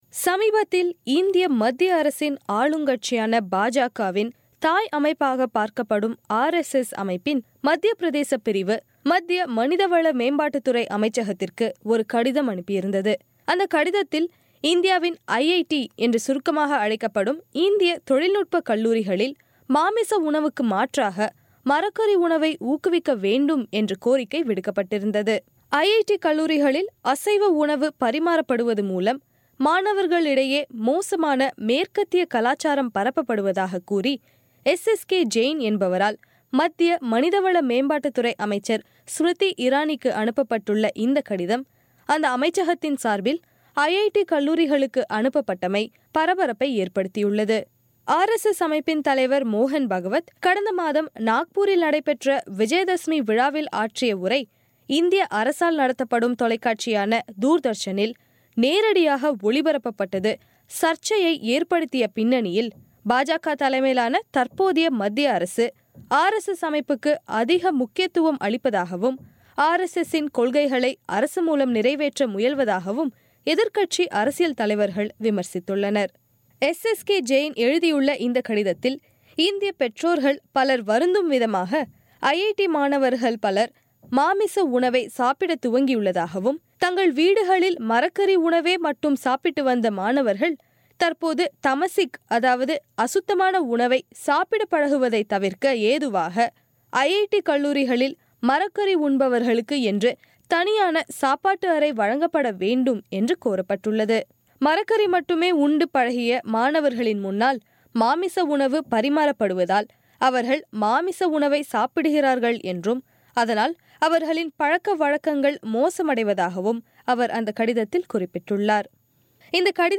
இதன் பின்னணி குறித்து ஆராயும் பெட்டகம்.